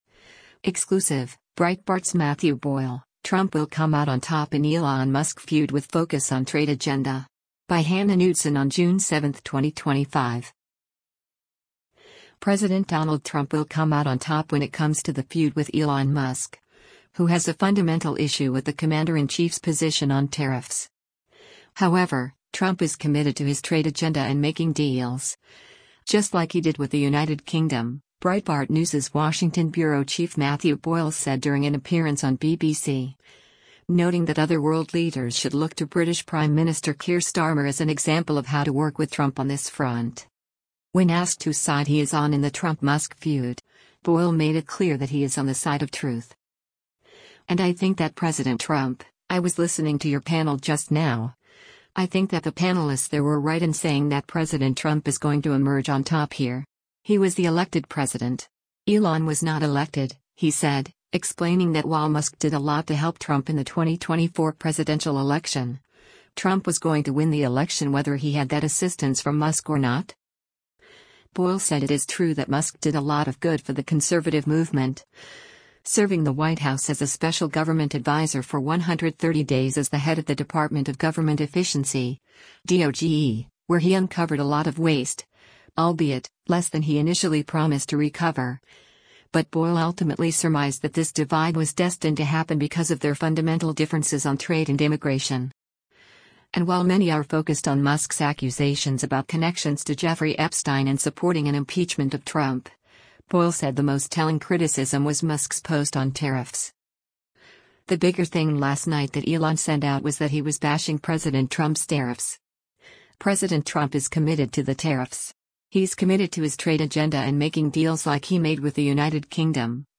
during an appearance on BBC